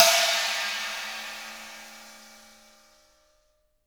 Index of /90_sSampleCDs/AKAI S6000 CD-ROM - Volume 3/Crash_Cymbal2/CHINA&SPLASH
S20CHINA+SIZ.WAV